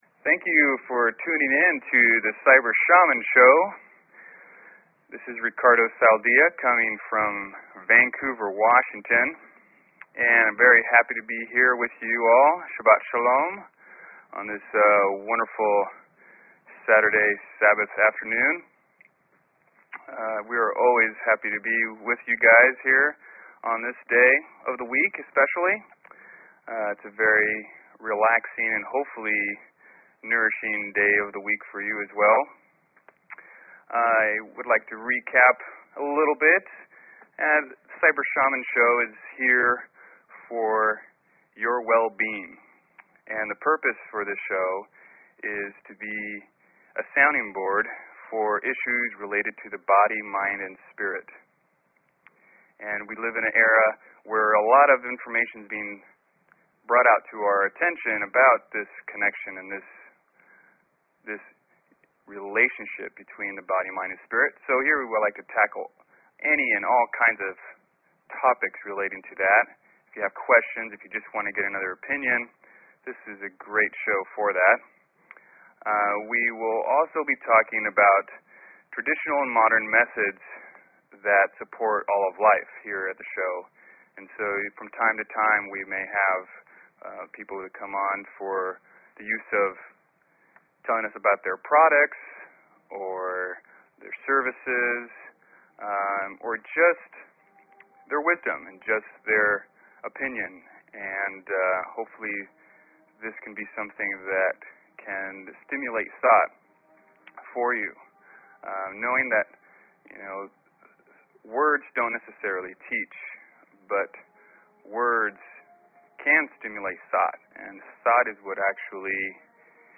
Talk Show Episode, Audio Podcast, Cyber_Shaman and Courtesy of BBS Radio on , show guests , about , categorized as